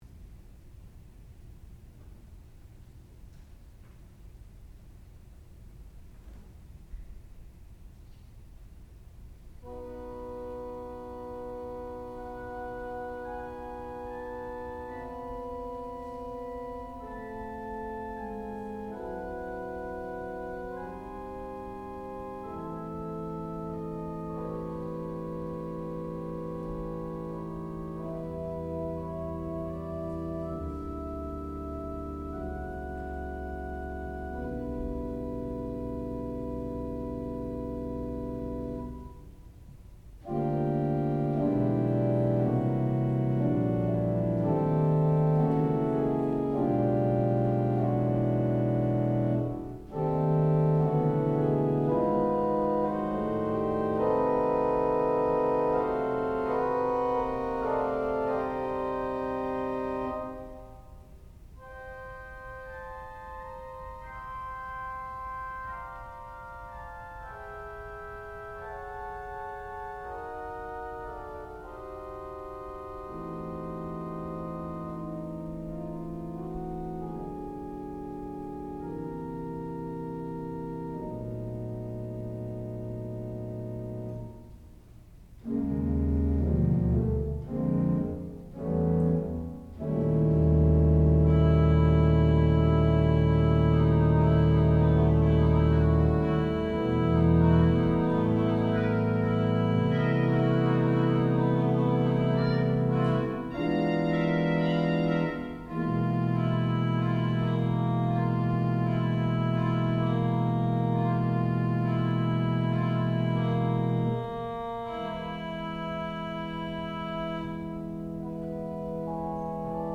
sound recording-musical
classical music
Master's Recital
organ